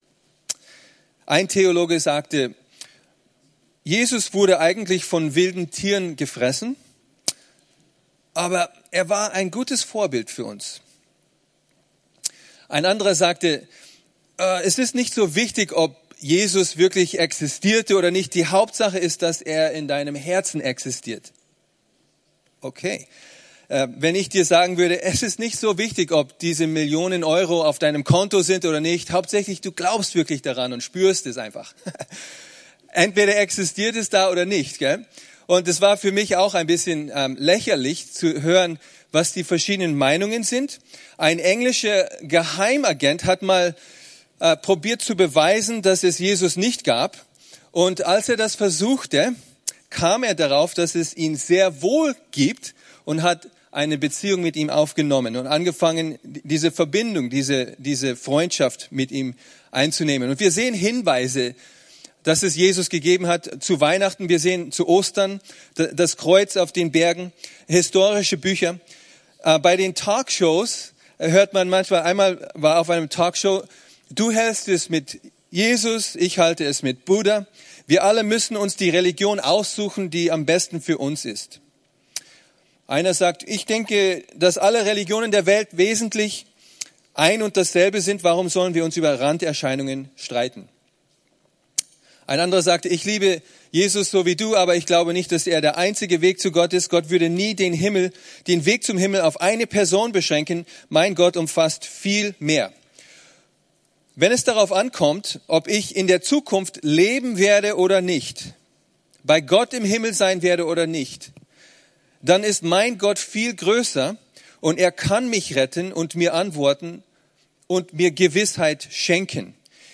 Vortrag 2 der Vortragswoche „ERlebt in Graz“